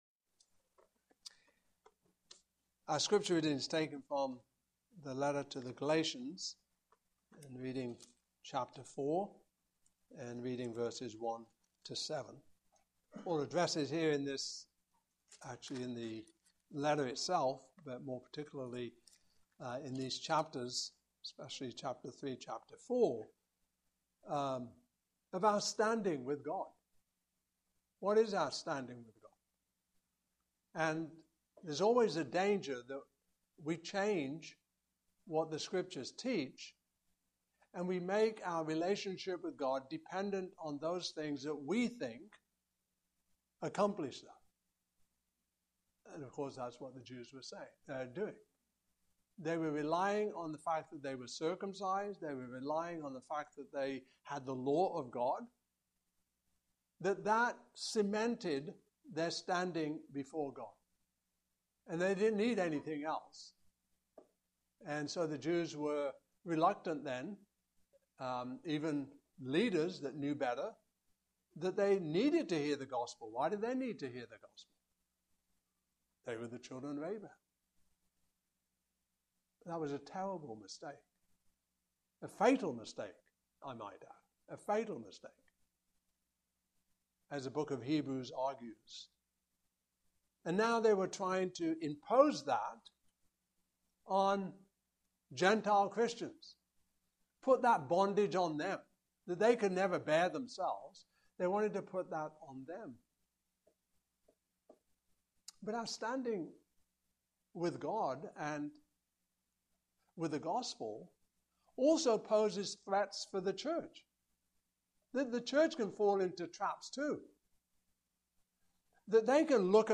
Series: Baptismal Service Passage: Galatians 4:1-7 Service Type: Morning Service « The Joy of Pentecost Creation